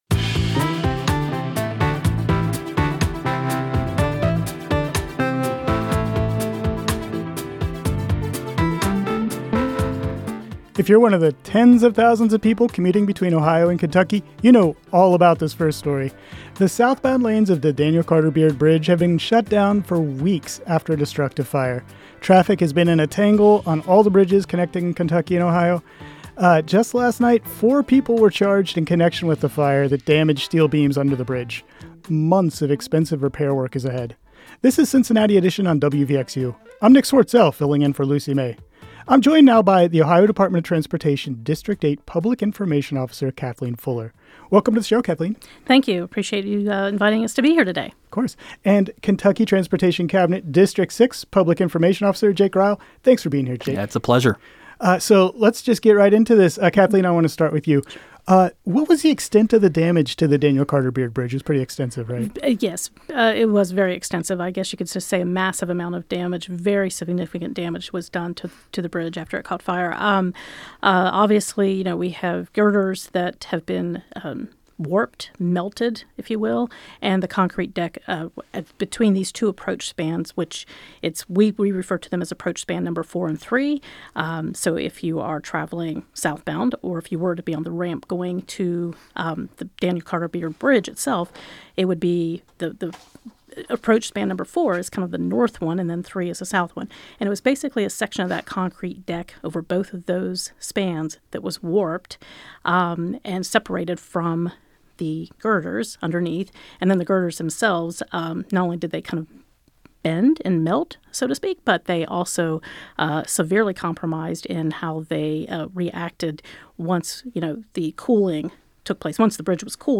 Cincinnati Edition - We talk to transportation officials about the work ahead after bridge fire